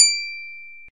Quick Ting Sound Effect Free Download